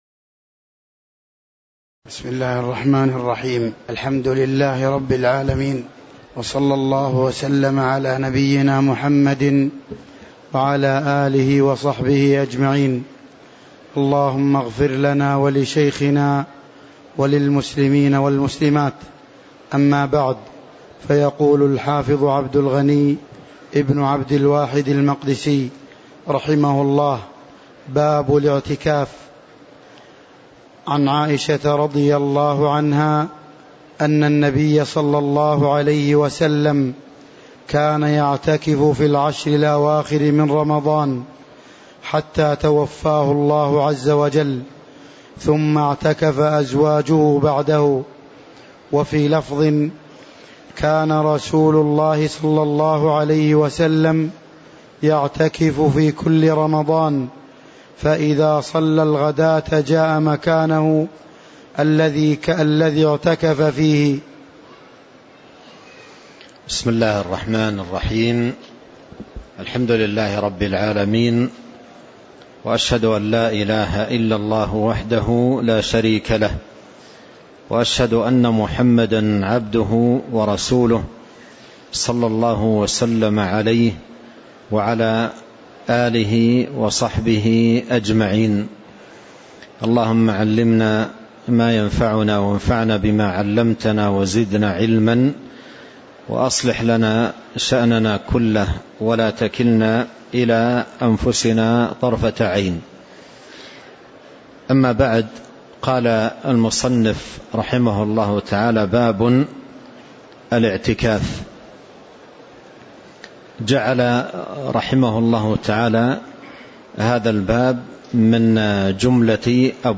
تاريخ النشر ٨ جمادى الآخرة ١٤٤٤ هـ المكان: المسجد النبوي الشيخ